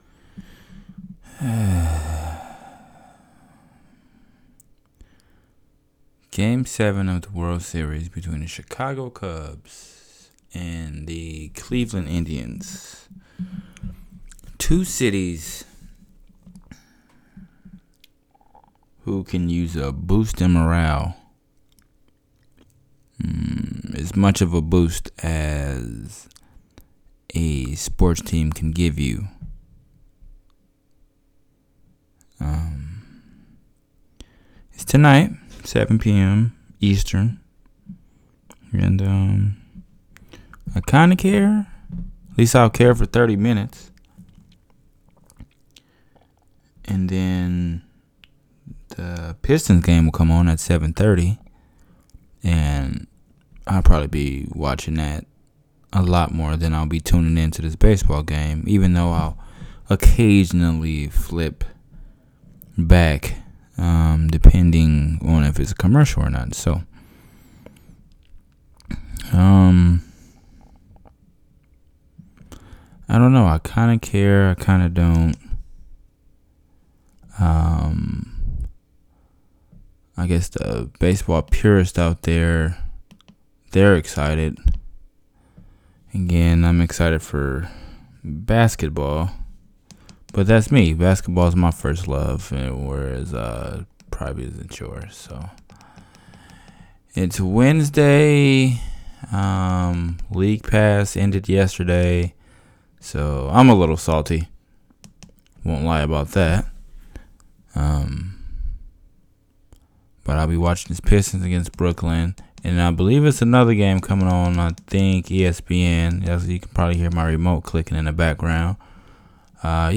Warning: I'm just ranting and rambling about random stuff, because I have a nice microphone now.